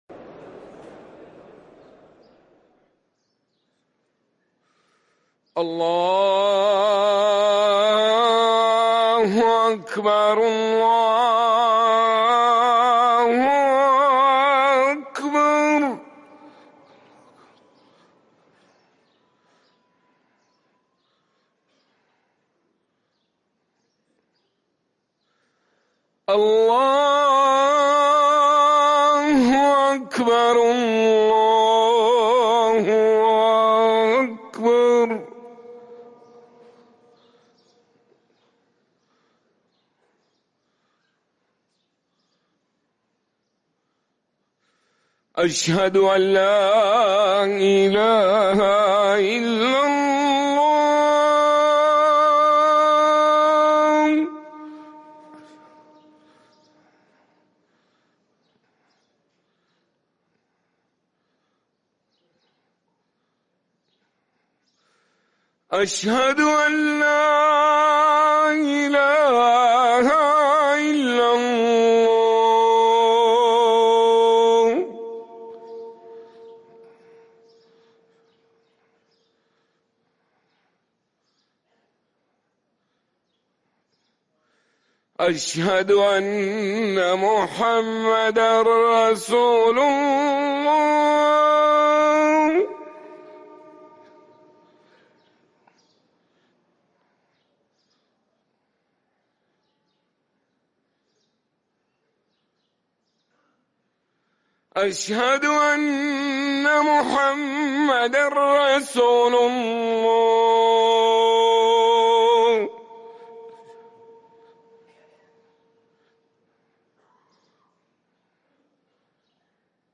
اذان الفجر
ركن الأذان